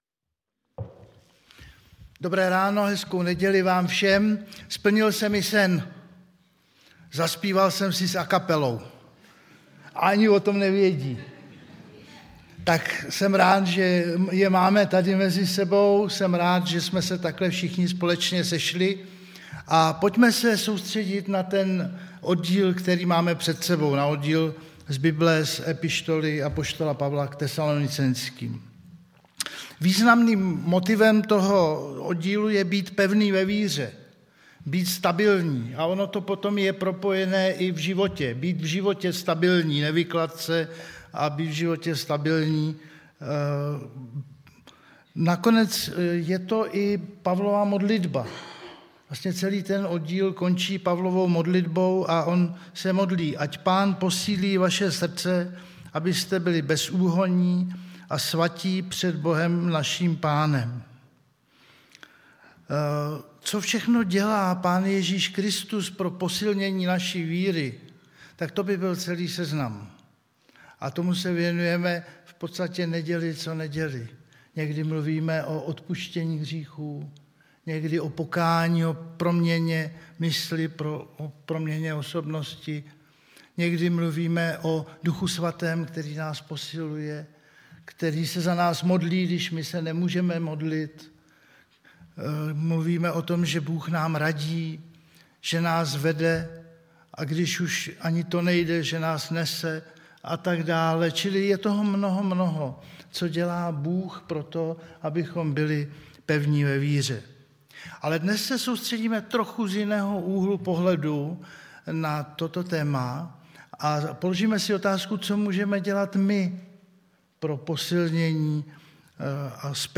Kategorie: Nedělní bohoslužby